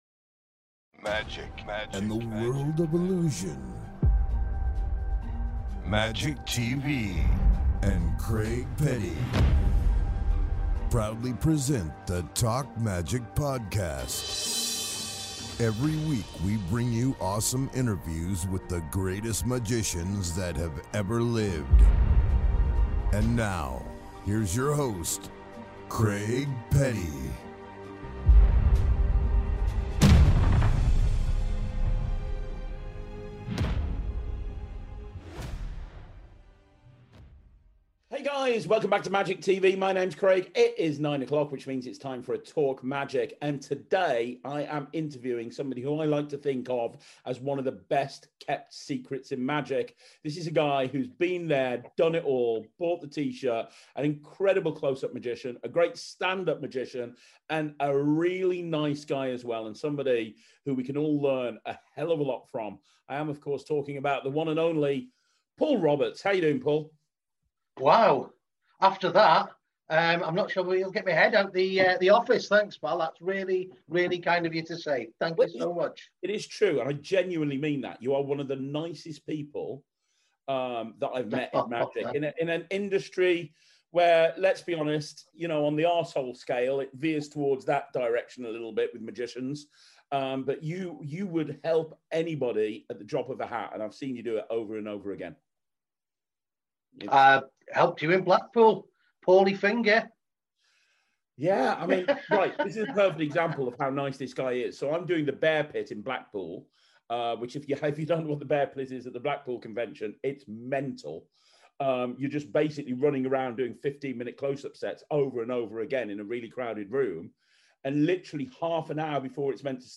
This interview is great!